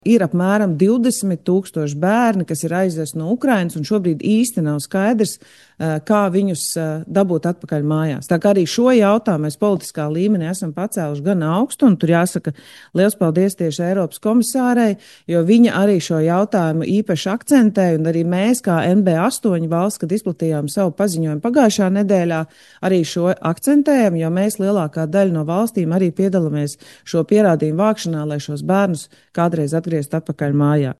Šo jautājumu potenciālajās miera sarunās uzturēs Labas gribas koalīcijas valstu līderi, kurā iesaistījusies arī Latvija, to pēc otrdienas valdības sēdes paziņoja Ministru prezidente Evika Siliņa.
Ministru prezidente Evika Siliņa: